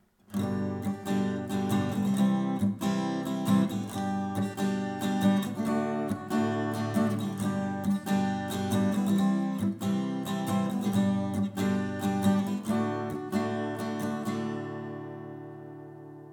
und unten kannst Du hören, wie sie mit einem einfachen Schlagmuster klingen.
I – IV – I – V (G-Dur)
I-IV-I-V-G-Dur.mp3